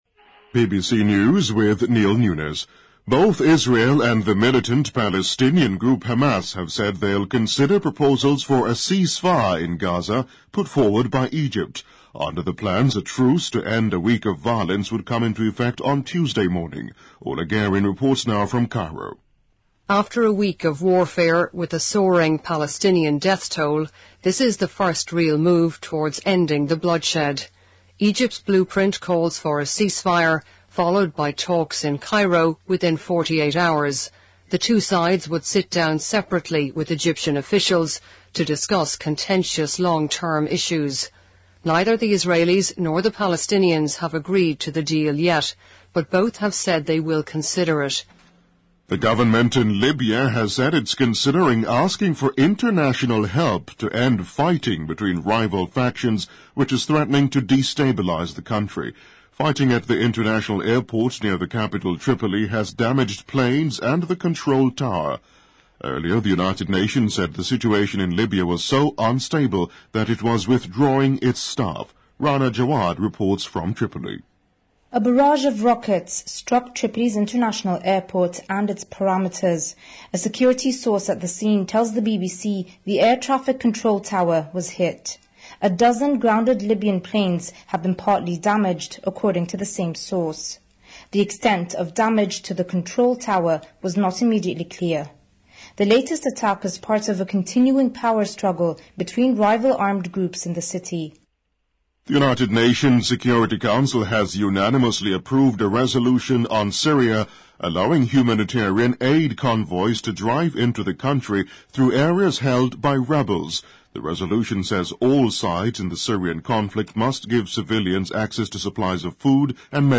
BBC news:中国当局开始对三名被控腐败罪的前高级官员进行刑事调查|BBC在线收听